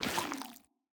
Minecraft Version Minecraft Version snapshot Latest Release | Latest Snapshot snapshot / assets / minecraft / sounds / block / sculk / step3.ogg Compare With Compare With Latest Release | Latest Snapshot
step3.ogg